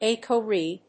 EcoRI (pronounced "eco R one") is an endonuclease enzyme isolated from strains of E. coli, and is part of the restriction modification system.